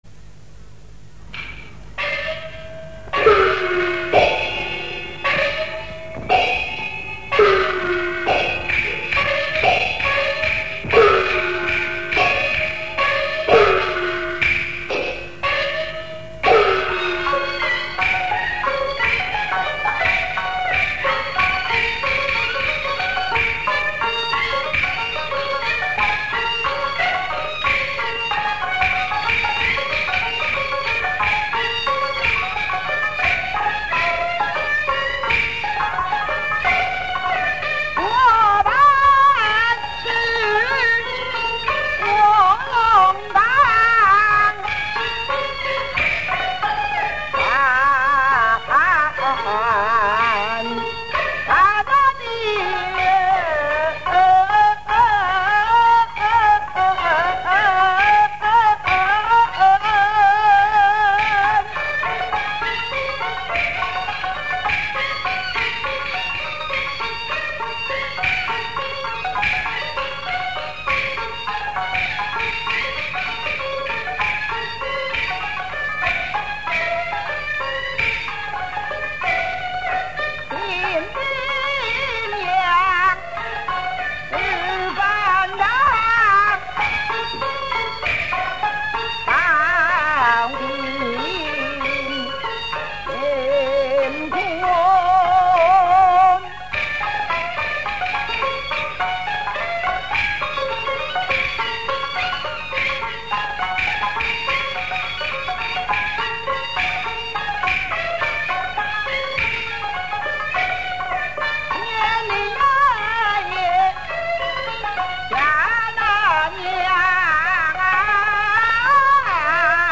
京剧 空城计 我本是卧龙岗散淡的人
请聆听谭富英大师的空城计录音